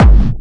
VEC3 Bassdrums Dirty 04.wav